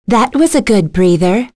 Lewsia_B-Vox_Victory.wav